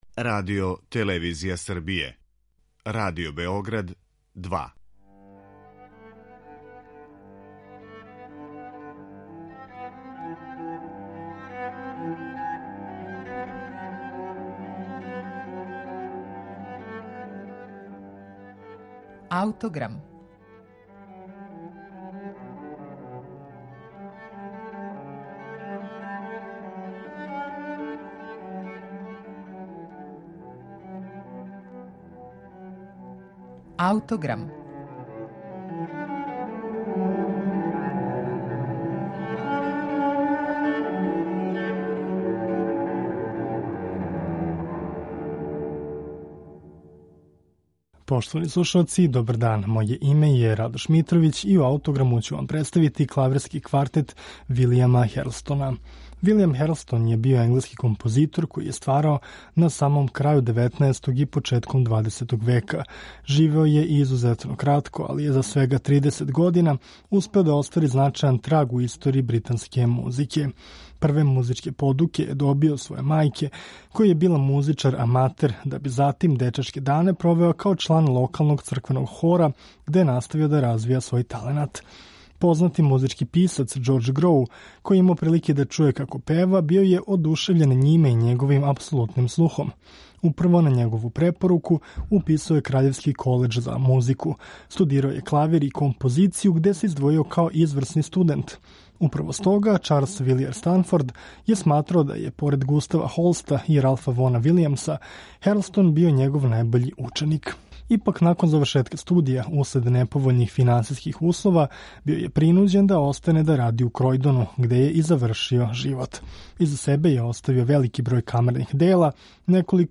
Вилијам Херлстон ‒ Клавирски квартет
Реч је о његовом најобимнијем и најамбициознијем камерном делу, а слушаћемо га у извођењу квартета „Тунел".